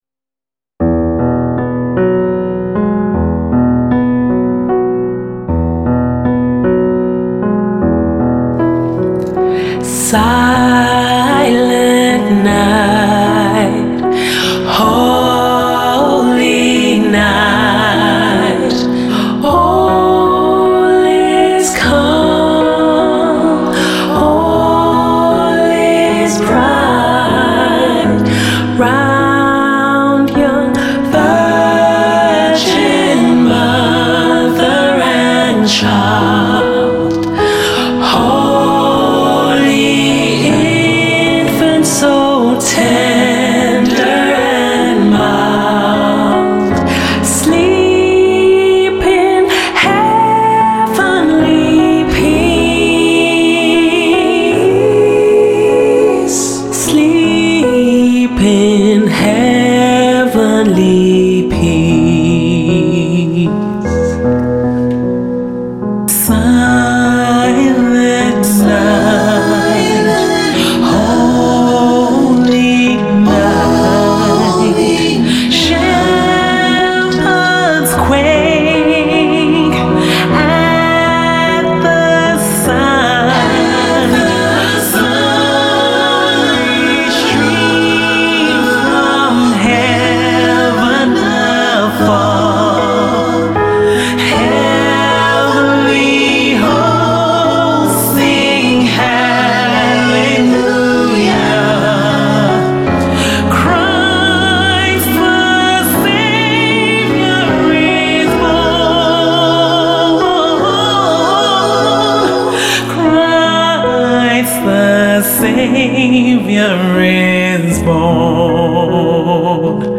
Merry Christmas From Afro Pop Trio SHiiKANE!
Season’s greetings from Afro Pop group, SHiiKANE!
Here’s their stellar rendition of Christmas Classic, “Silent Night”.
Play SHiiKANE – Silent Night